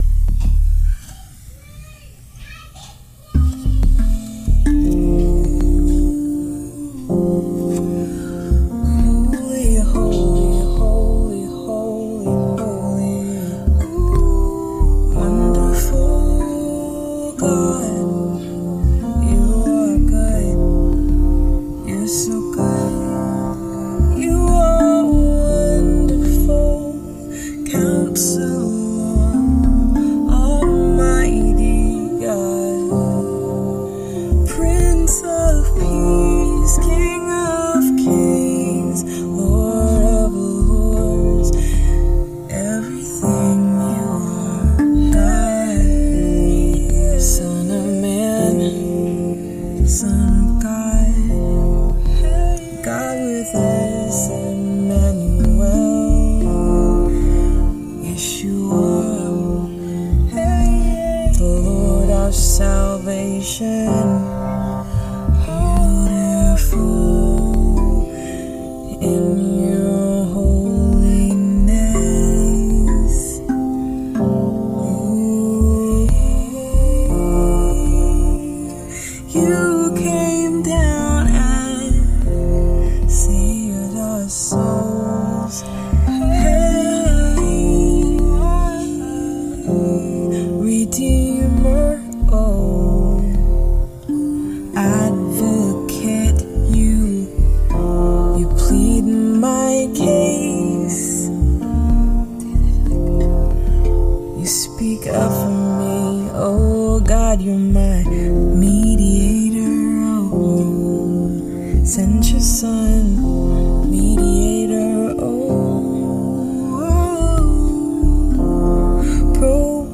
Spontaneous: A Voice Behind Me, No Other Name Rav Vast & Beats Sessions 10-29-24